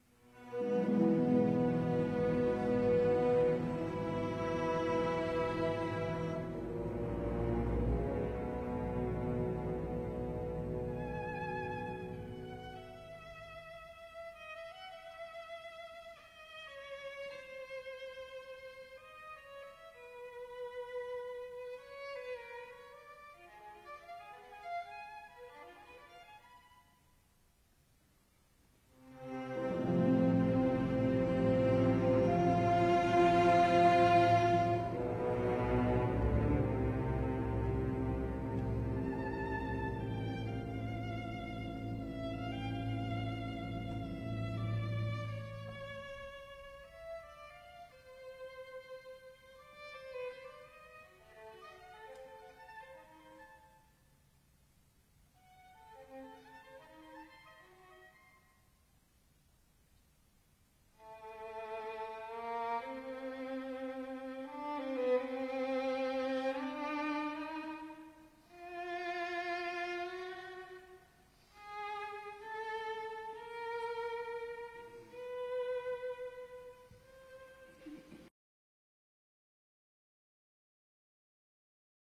Violin: R. Strauss: Ein Heldenleben, Reh. 22-32 (Concertmaster Solo) – Orchestra Excerpts
Ein Heldenleben violin excerpt